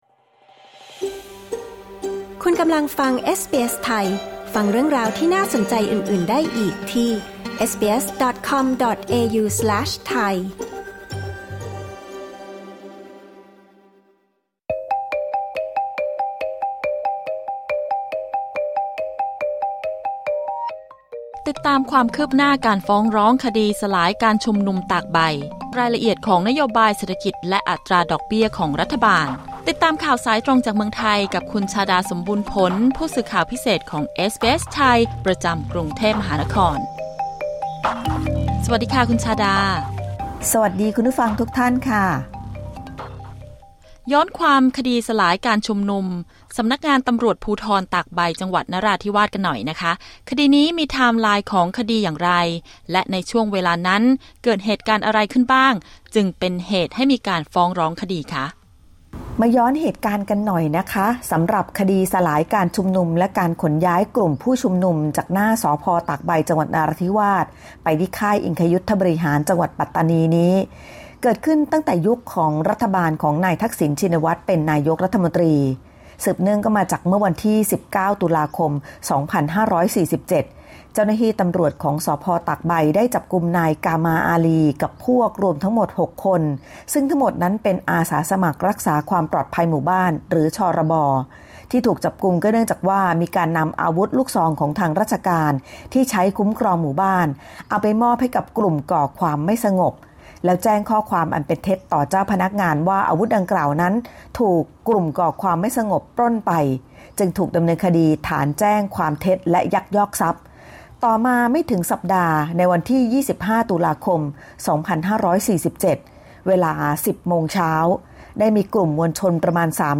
กด ▶ ฟังรายงานข่าวด้านบน